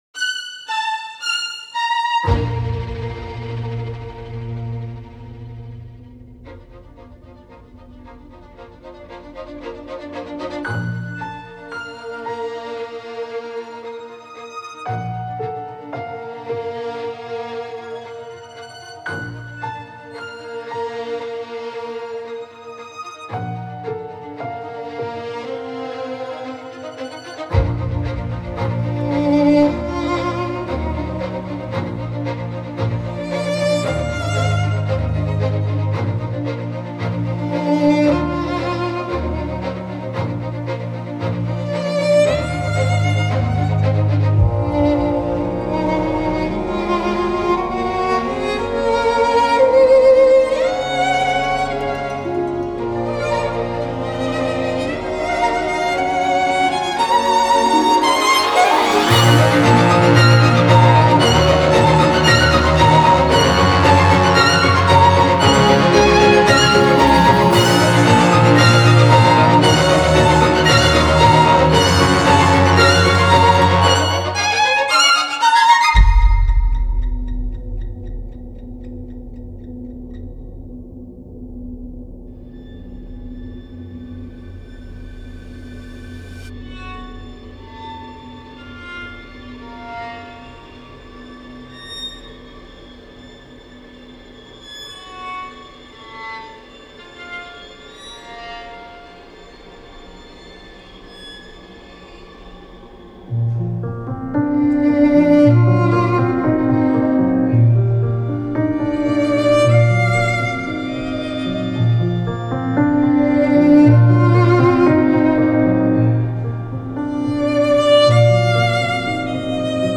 Аранжирую, запишу живые струнные в различных составах.